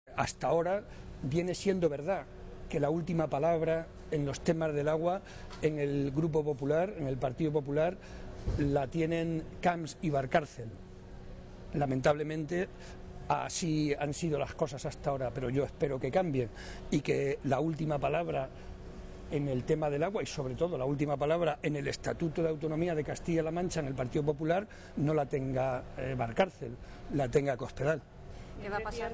Momento de la clausura del Foro Joven de JSCM